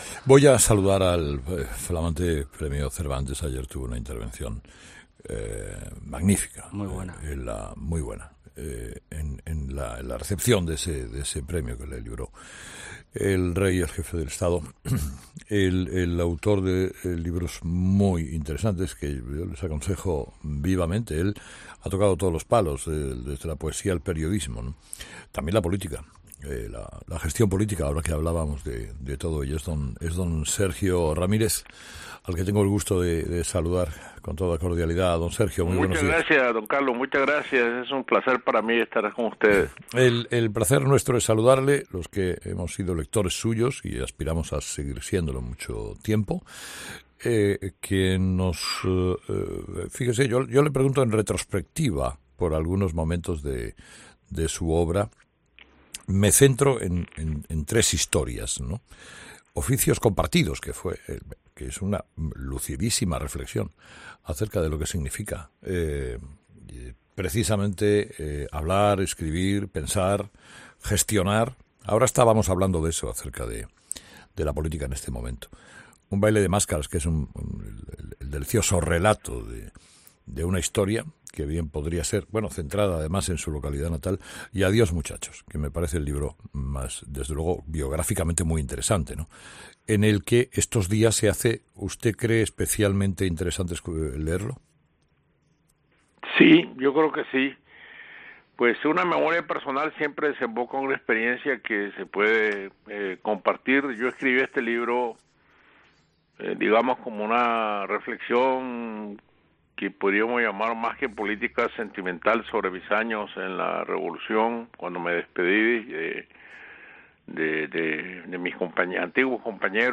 Entrevista a Sergio Ramírez, premio Cervantes